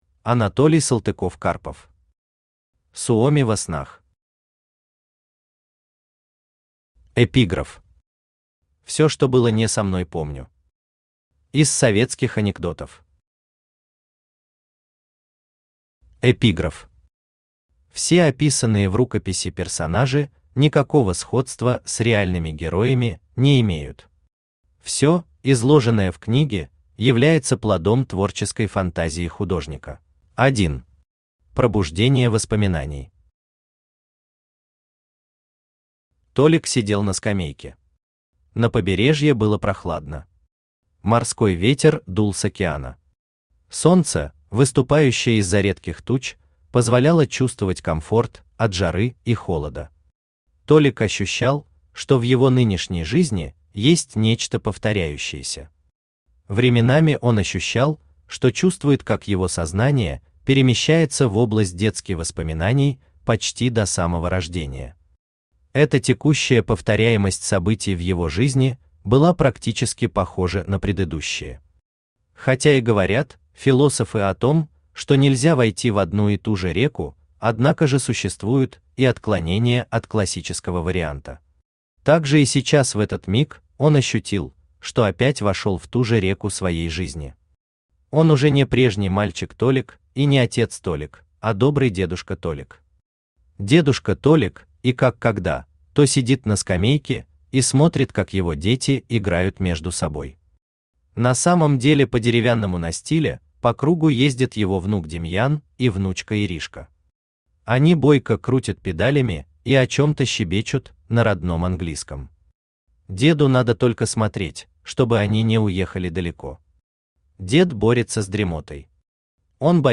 Аудиокнига Суоми во снах | Библиотека аудиокниг
Aудиокнига Суоми во снах Автор Анатолий Сергеевич Салтыков-Карпов Читает аудиокнигу Авточтец ЛитРес.